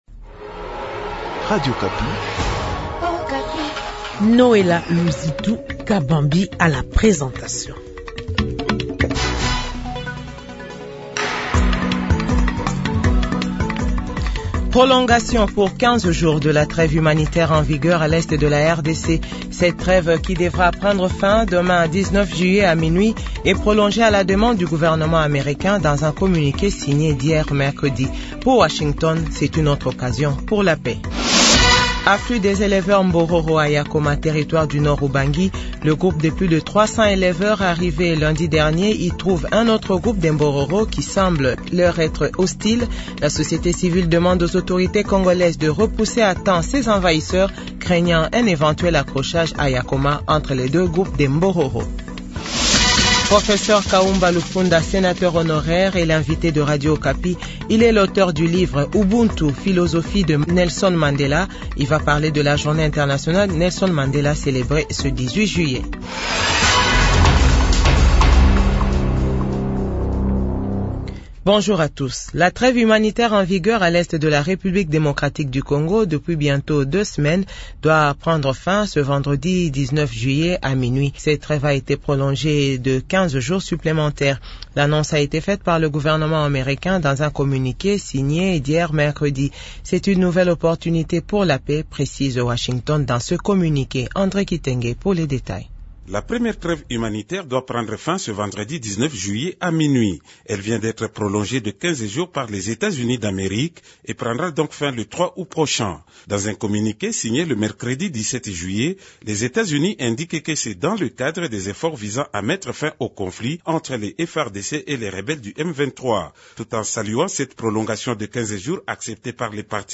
JOURNAL FRANCAIS DE 15H00